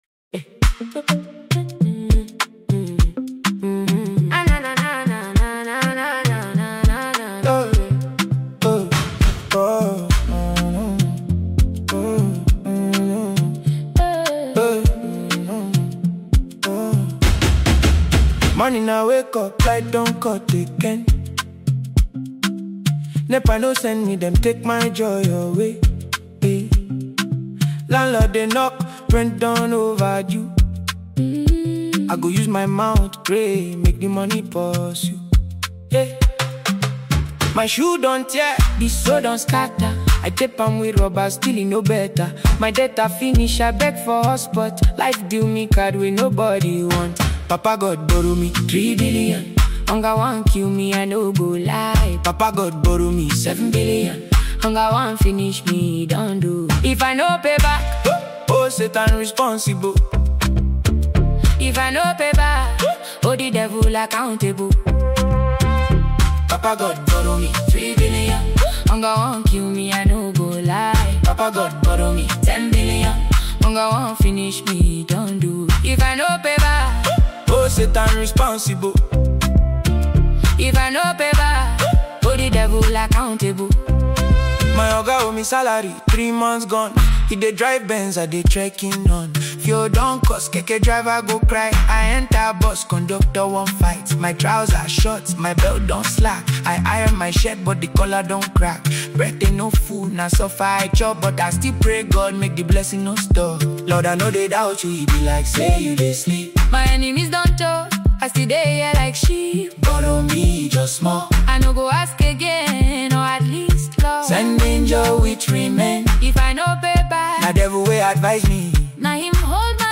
strong vocals, emotional depth